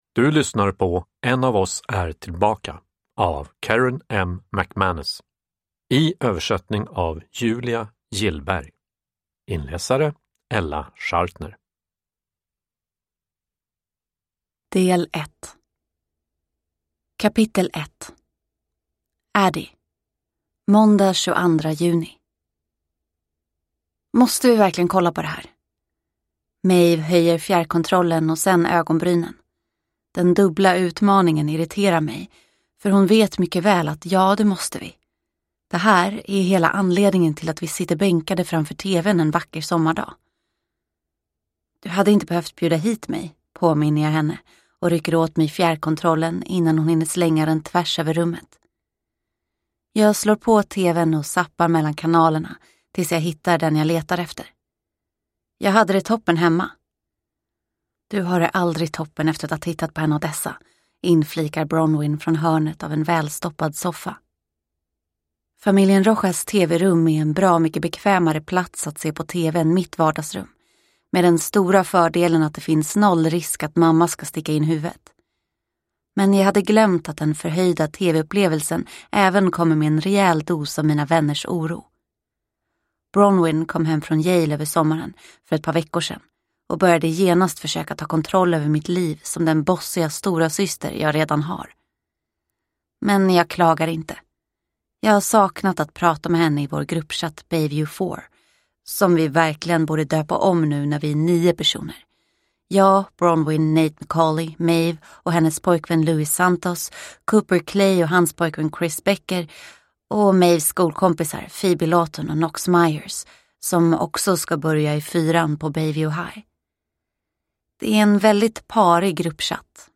En av oss är tillbaka – Ljudbok – Laddas ner